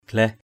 /ɡ͡ɣlɛh˨˩/ (t.) mệt = las, fatigué. mai mâng glaih =m m/ =g*H mới đến còn mệt = arriver d’un voyage fatigant. glaih glar =g*H g*R mệt mỏi, vất vả, khó...